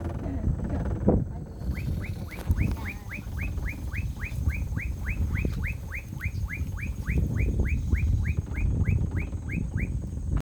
Angú (Donacobius atricapilla)
Nombre en inglés: Black-capped Donacobius
Localización detallada: Camping Laguna Iberá
Condición: Silvestre
Certeza: Fotografiada, Vocalización Grabada